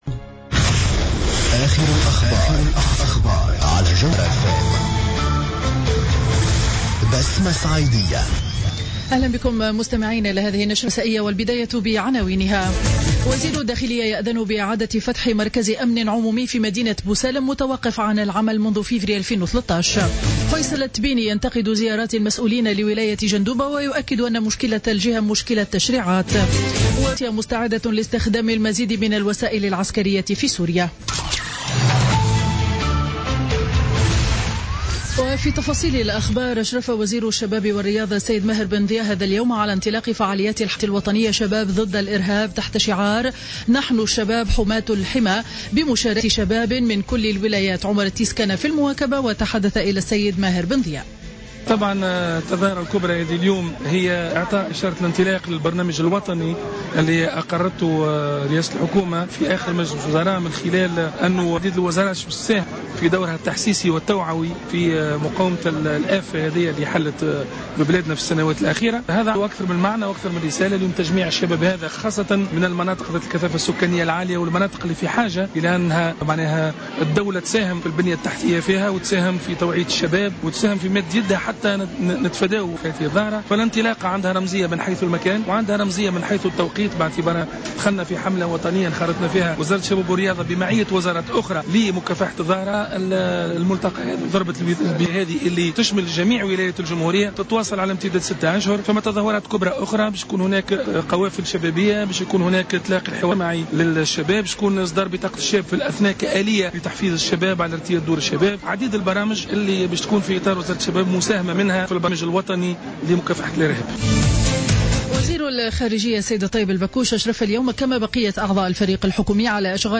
نشرة أخبار السابعة مساء ليوم السبت 19 ديسمبر 2015